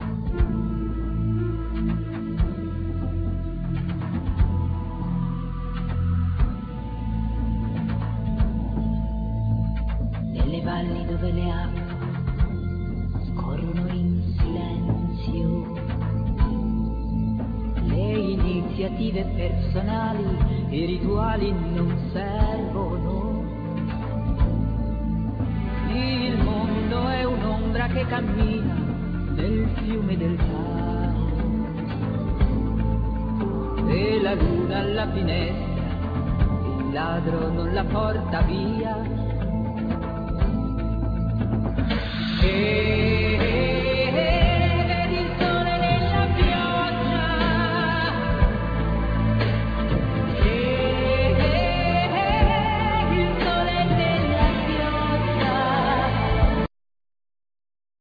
Vocal
Bass
Trumpet
Guitars